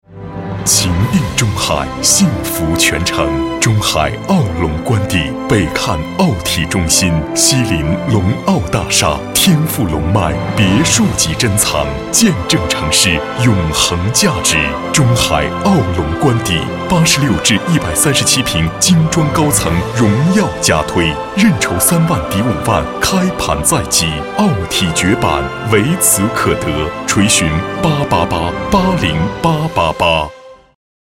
女粤21_广告_促销_马牌轮胎_欢快
标签： 欢快
配音风格： 年轻 欢快 亲切